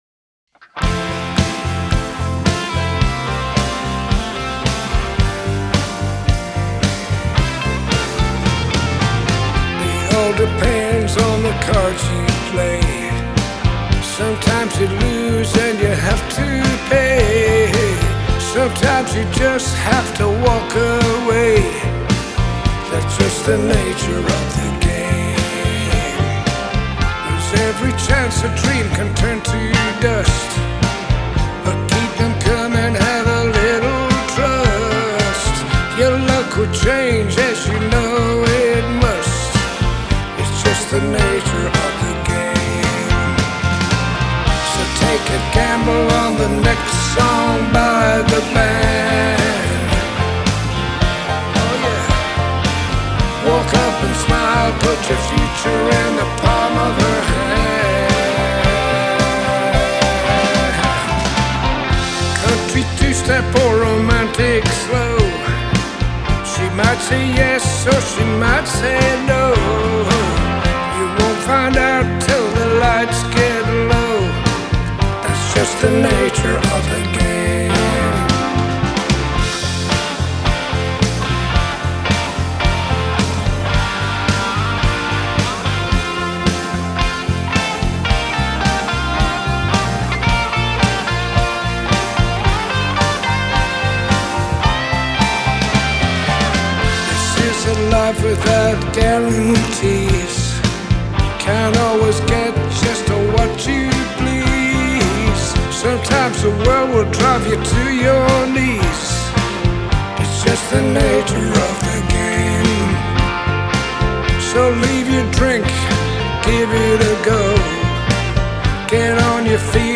vocals, keyboards, and lead guitars
I also like this mix I did better...has more punch.
Key=F , Tempo 110, Length (m:s)=3:11
Style is _GLOW_UP.STY (Glow Up Honky Tonk Country Rock)
RealTracks in style: 2613:Bass, Electric, FunkPumpingOffbeat8ths Ev 100
RealTracks in style: 2634:Guitar, Electric, Rhythm CountryBluesRock Ev 110
RealDrums in style:BluesRockEv^1-a:Snare, HiHat , b:Snare, Open HiHat
Especially love his lead guitar fills.